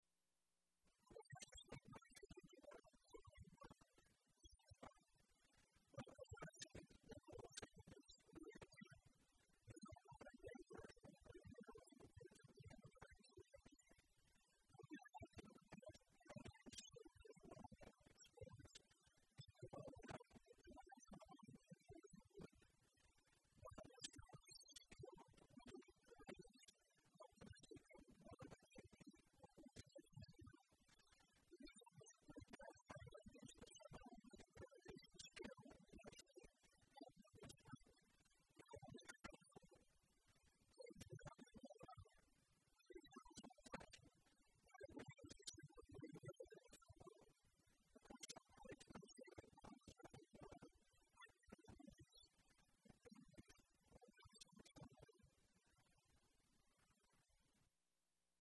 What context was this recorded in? Diplomatic Reception Room.